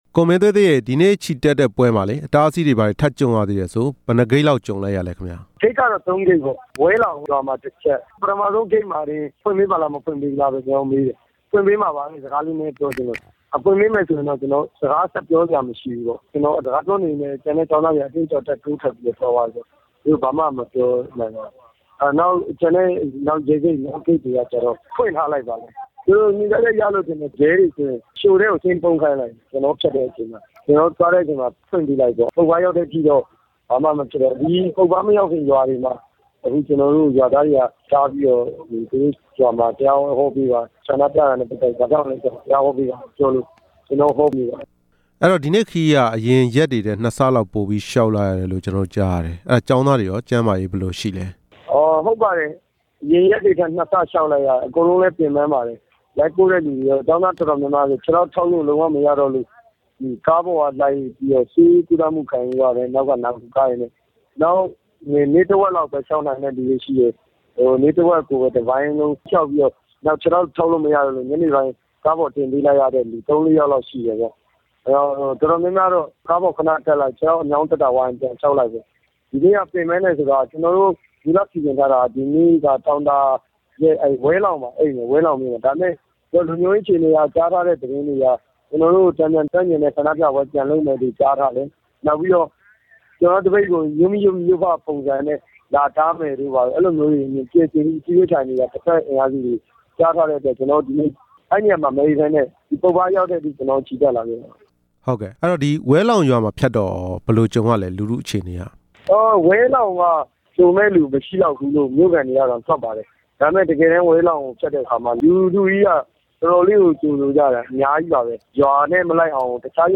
ကျောင်းသားစစ်ကြောင်း နောက်ဆုံးအခြေအနေ မေးမြန်းချက်